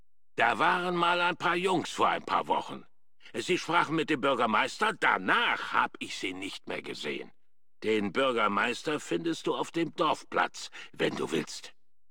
FOBOS-Dialog-Armpit-002.ogg